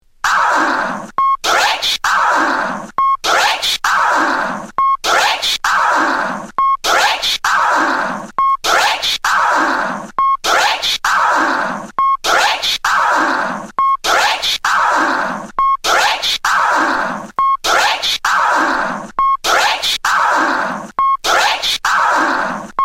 Category       レコード / vinyl LP
Tag       HIP HOP PARTYTRACS